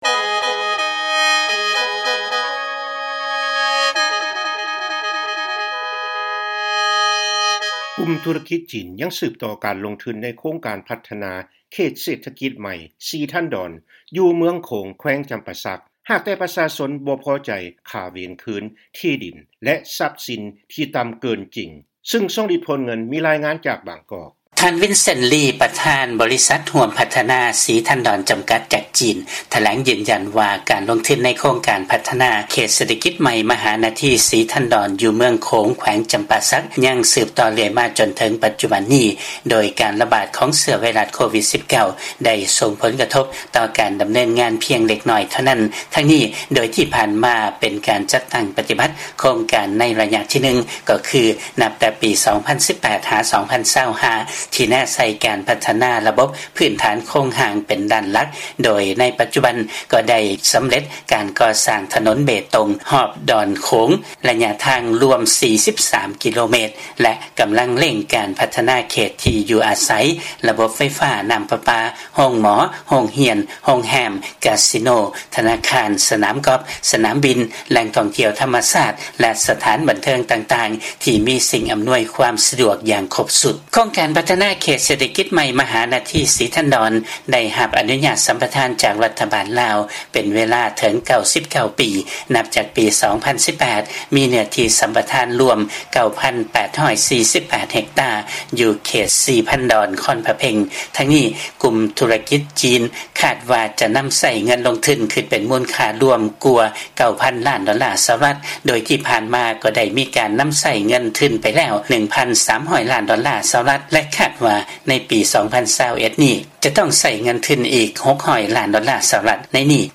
ຟັງລາຍງານ ກຸ່ມທຸລະກິດ ຈີນ ຍັງສືບຕໍ່ ການລົງທຶນໃນໂຄງການ ພັດທະນາເສດຖະກິດໃໝ່ ສີທັນດອນ ຢູ່ເມືອງໂຂງ ແຂວງຈຳປາສັກ